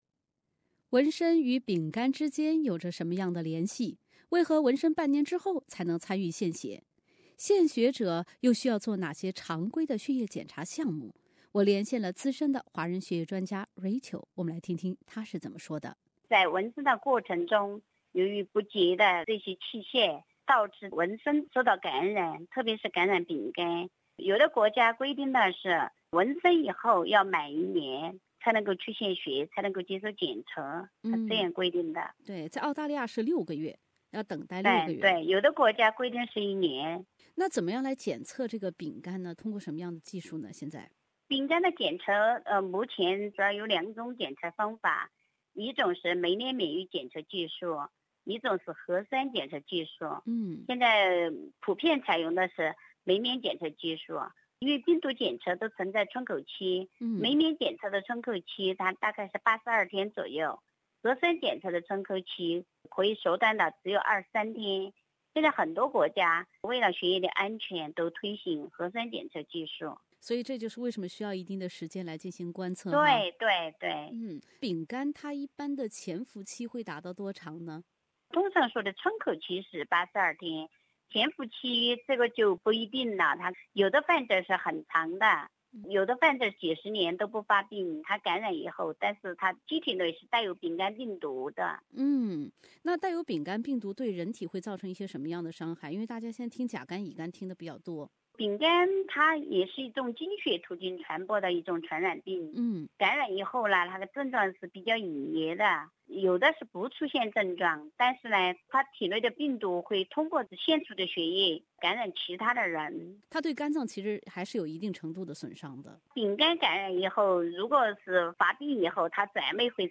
资深血液病专家